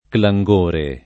[ kla jg1 re ]